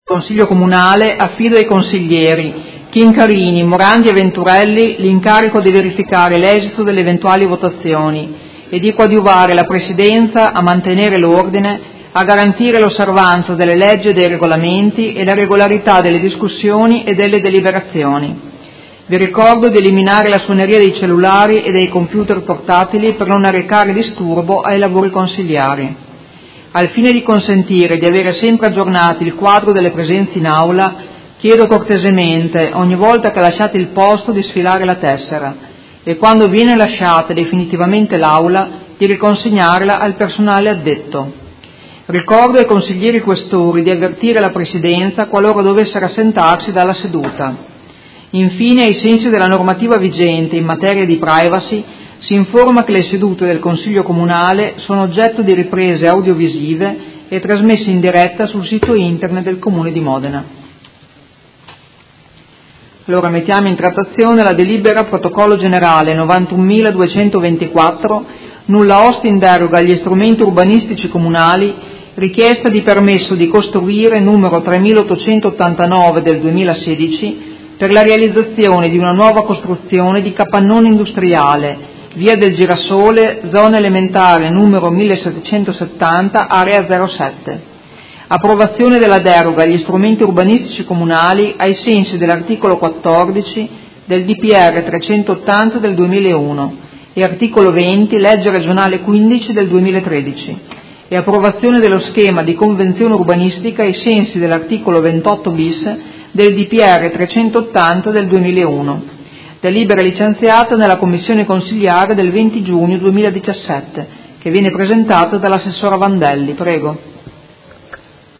Seduta del 13/07/2017 Apre i lavori del Consiglio.
Presidentessa